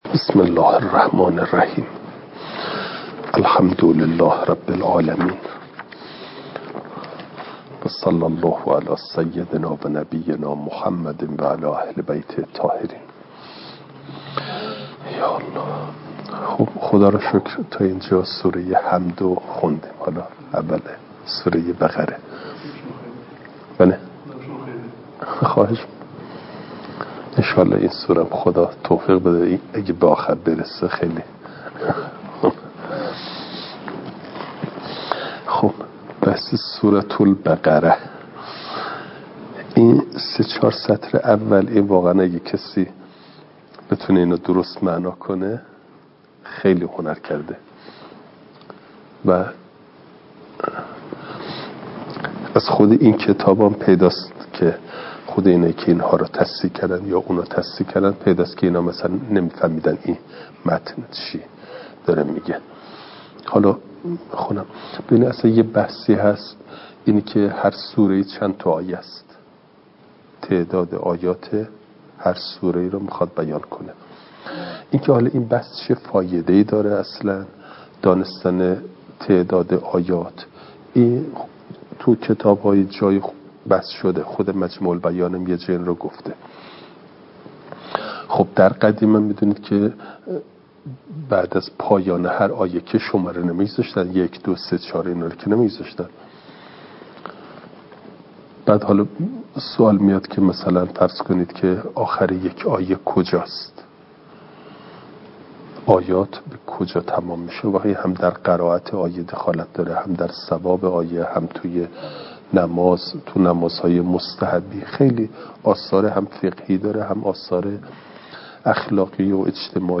فایل صوتی جلسه چهاردهم درس تفسیر مجمع البیان